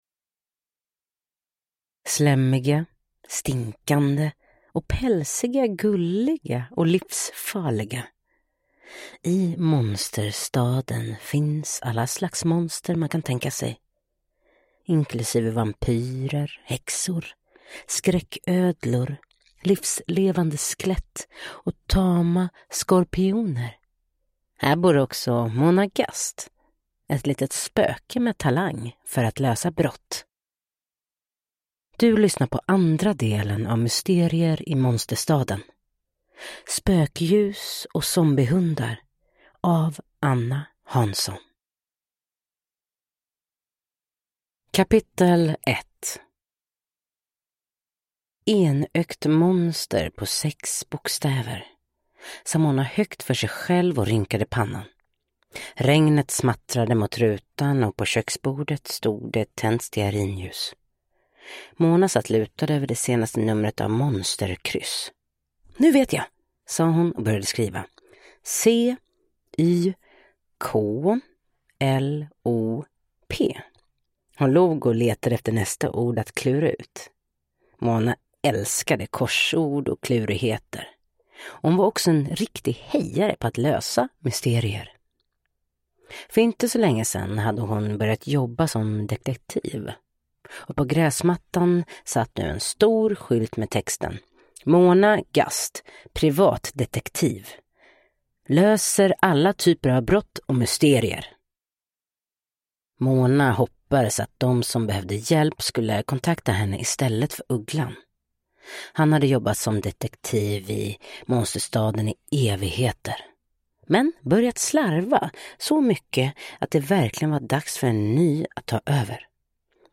Spökljus och zombiehundar – Ljudbok